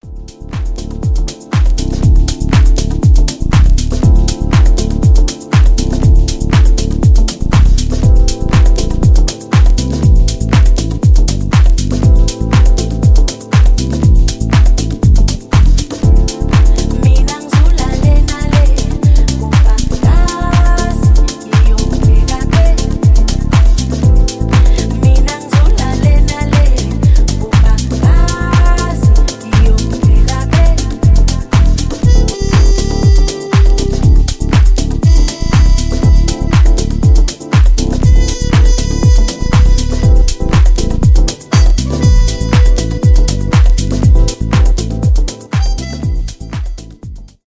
саксофон , танцевальные , chillout
deep house , balearic house , lounge